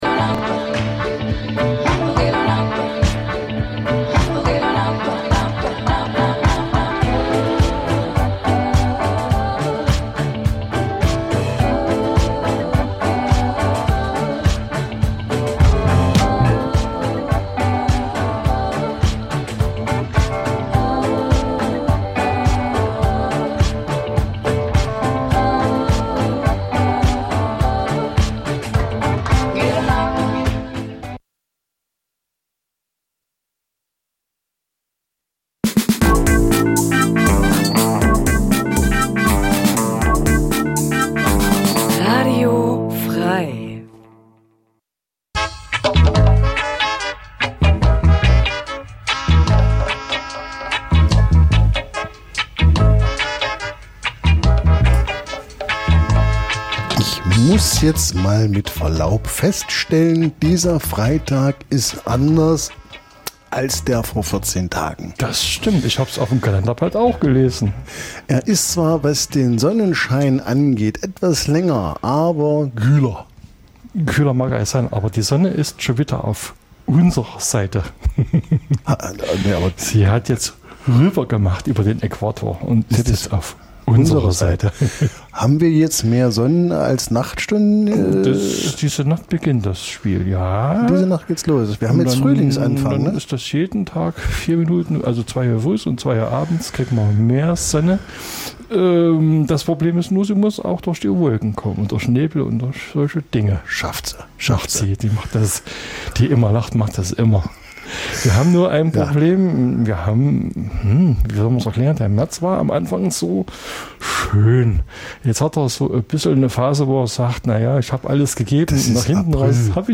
Musik f�rs Herz. Reggae, Ska, Dub von alt bis neu.